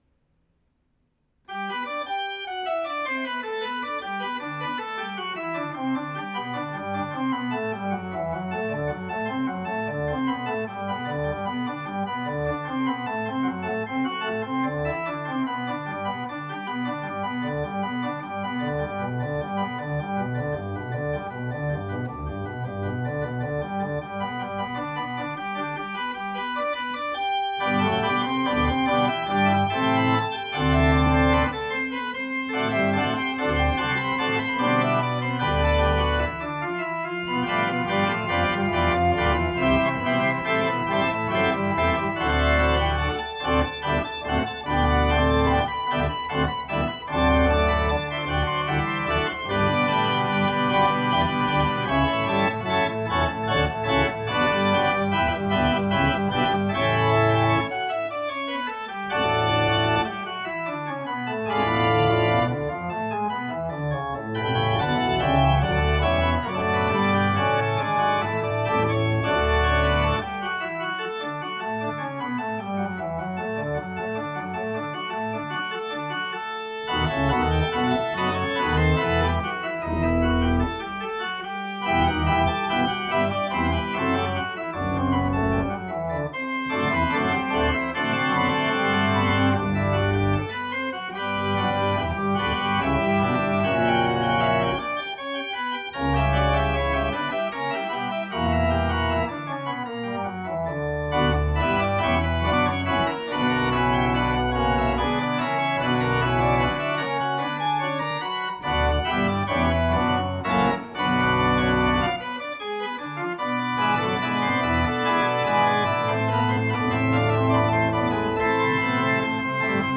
NACHSPEL: Bach G-Dur Präludium